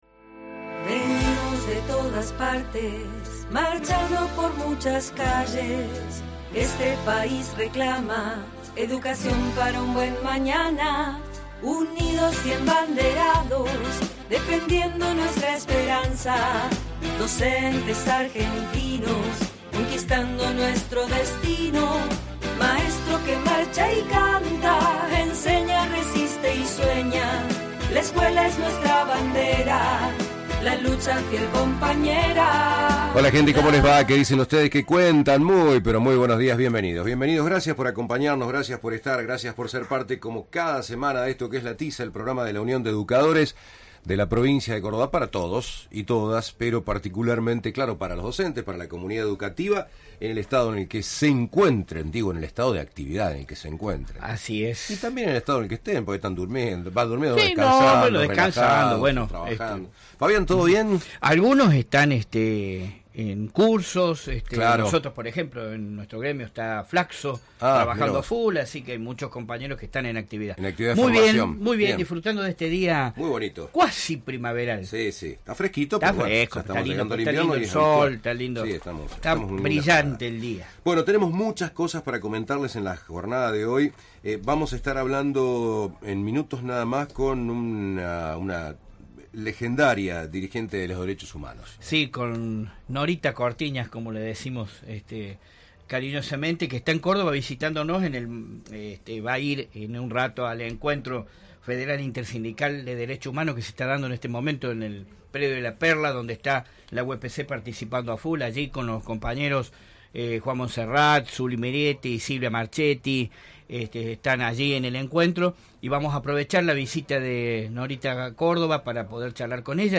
El Sindicato cuenta con el programa de radio "La Tiza", en el cual se reflejan las temáticas gremiales, educativas y pedagógicas que interesan a los y las docentes.
El programa se emite los sábados de 12 a 13 hs por radio Universidad (AM 580) y de 16 a 17 hs por Más que música (FM 102.3).